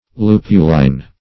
lupuline - definition of lupuline - synonyms, pronunciation, spelling from Free Dictionary
Lupuline \Lu"pu*line\, n. [NL. lupulus the hop, fr. L. lupus the